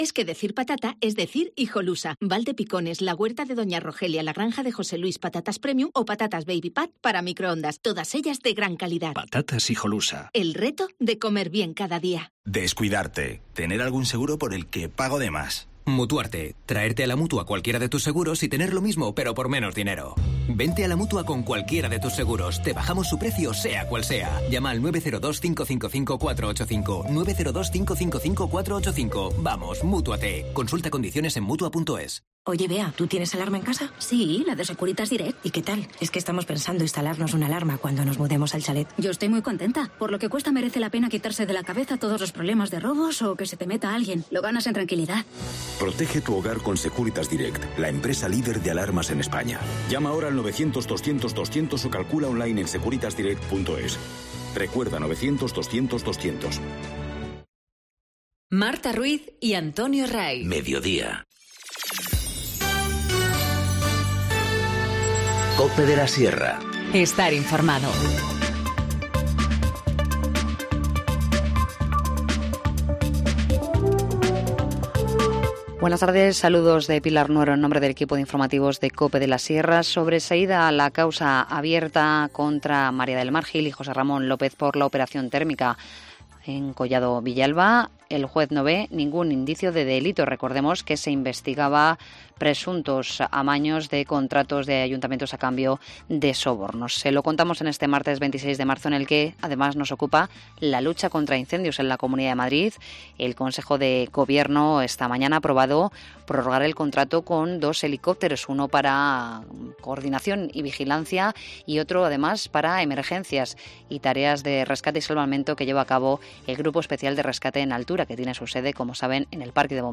Informativo Mediodía 26 marzo 14:20h